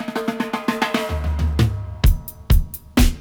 129-FILL-FX.wav